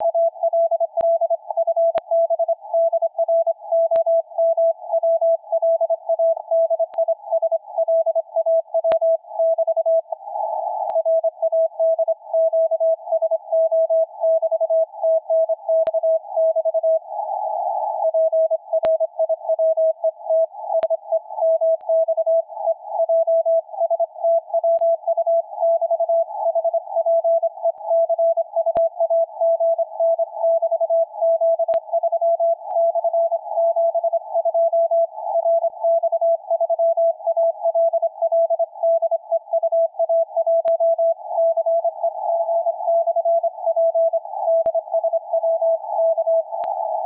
Частота немного плавала, но в пределах полосы пропускания фильтра 300Гц :) В процессе запсиси немного подстраивался.
Реальные 3 ватта и лесные условия.